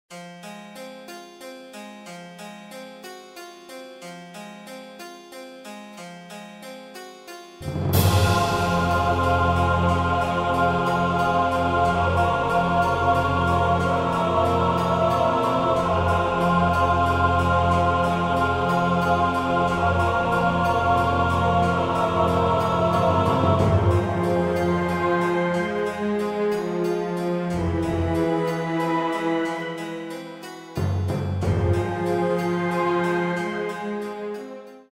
The ULTIMATE haunted house CD!